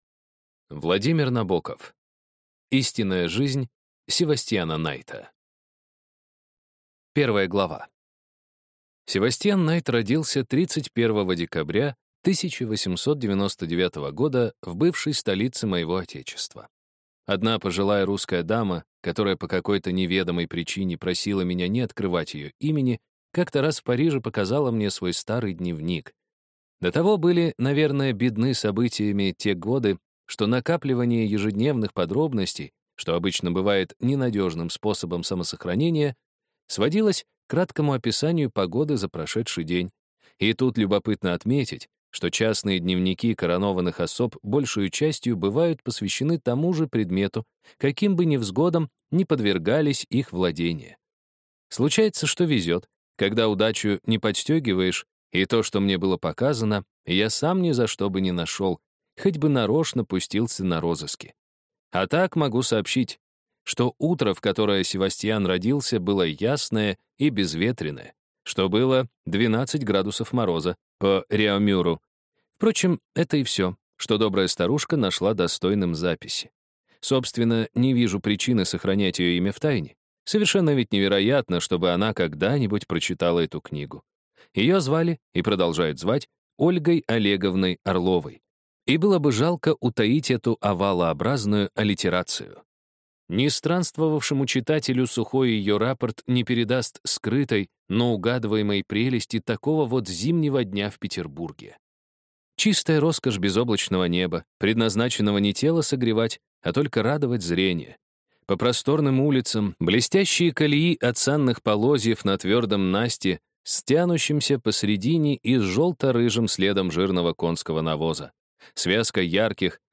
Аудиокнига Истинная жизнь Севастьяна Найта | Библиотека аудиокниг